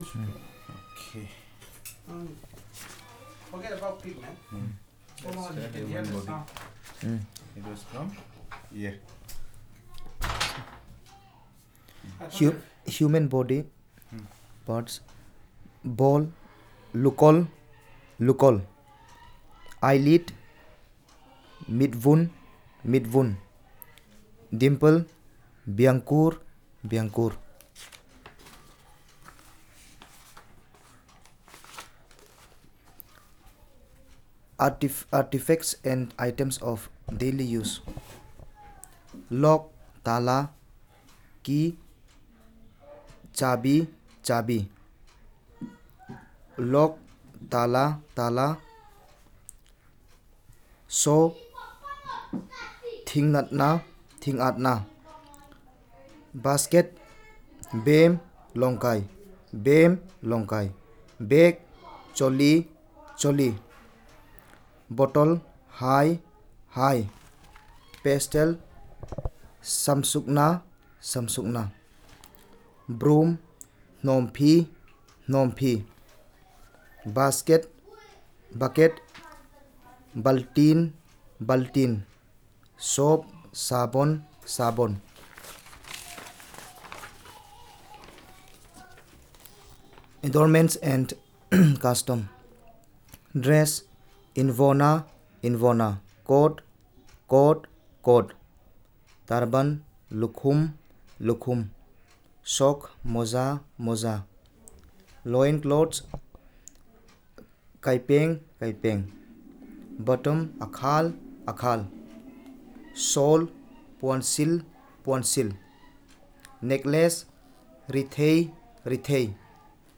Elicitation of words of multiple domains